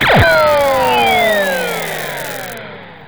Laser 2
laser_2.wav